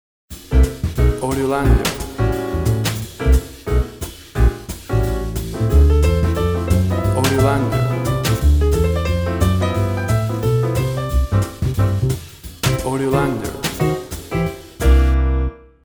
clasic jazz trio.
Tempo (BPM) 130